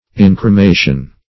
Search Result for " incremation" : The Collaborative International Dictionary of English v.0.48: Incremation \In`cre*ma"tion\, n. Burning; esp., the act of burning a dead body; cremation.